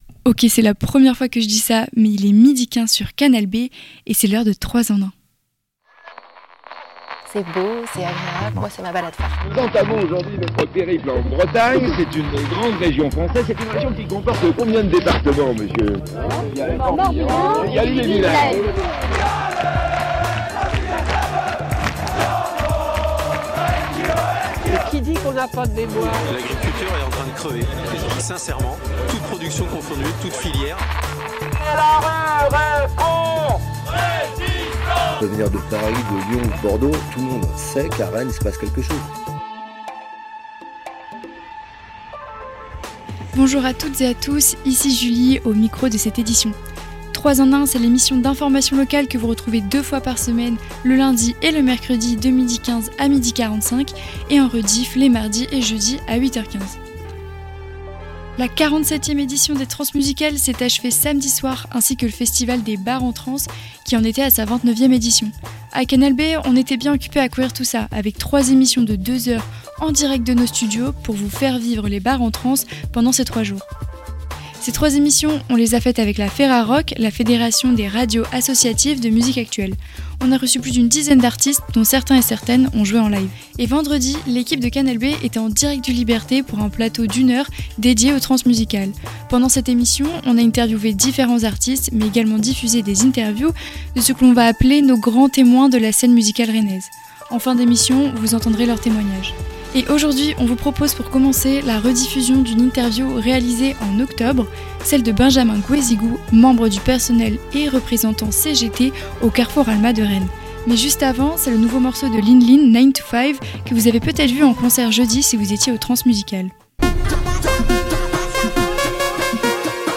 Rediff de l'entretien